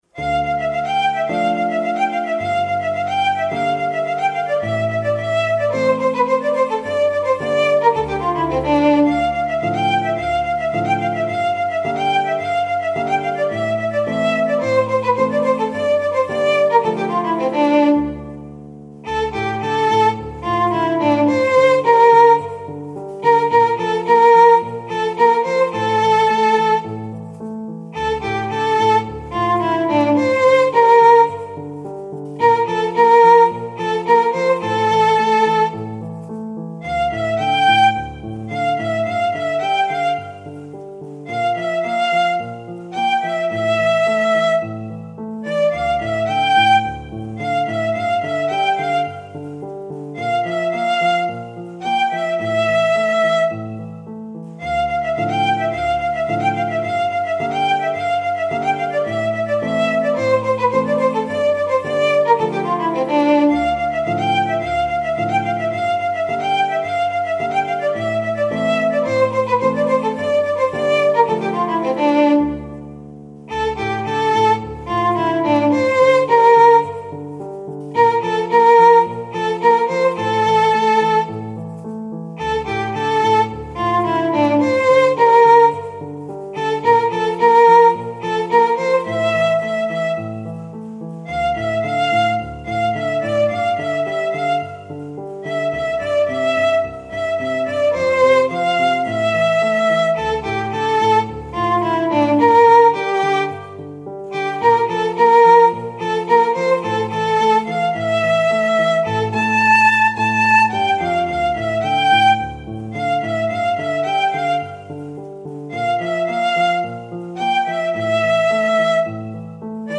ویولون